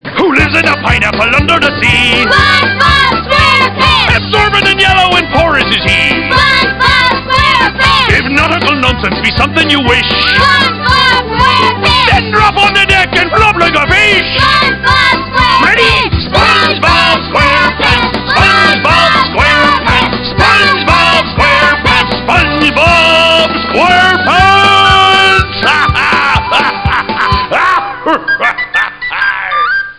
TV & Film Themes